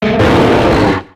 Cri de Terrakium dans Pokémon X et Y.